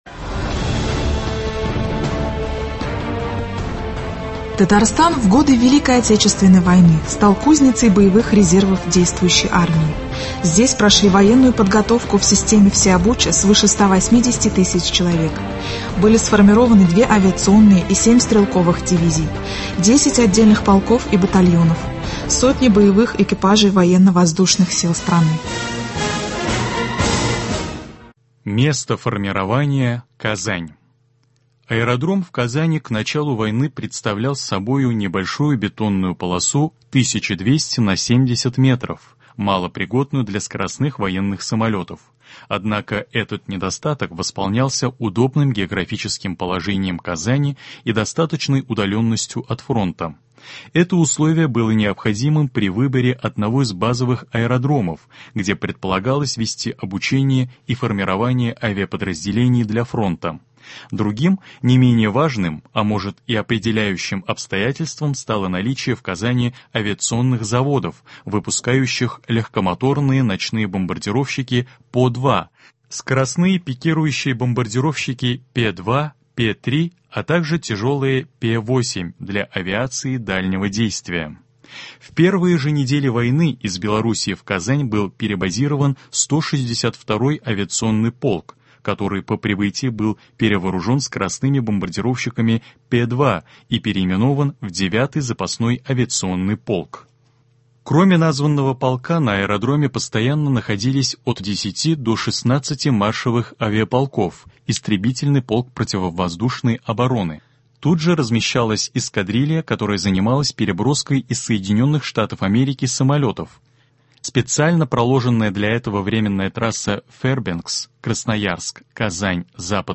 Звучит очерк об авиаторах Татарстана в годы войны.